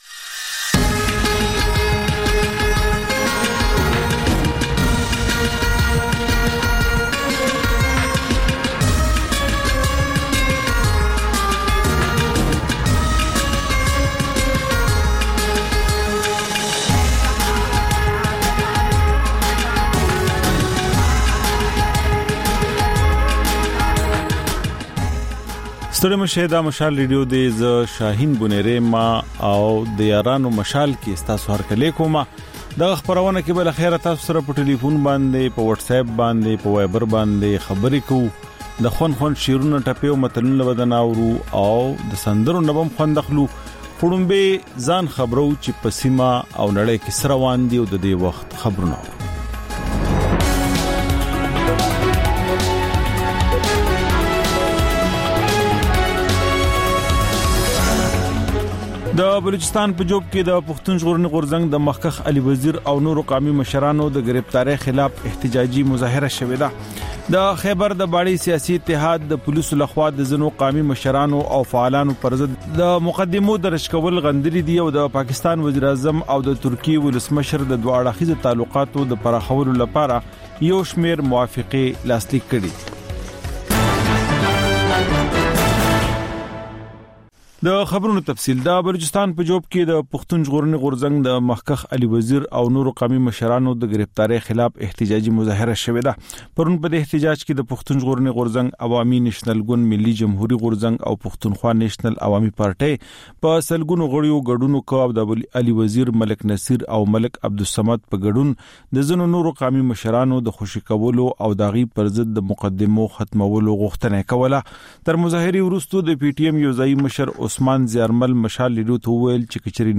د یارانو مشال په ژوندۍ خپرونه کې له اورېدونکو سره بنډار لرو او سندرې خپروو. دا یو ساعته خپرونه هره ورځ د پېښور پر وخت د ماخوستن له نهو او د کابل پر اته نیمو بجو خپرېږي.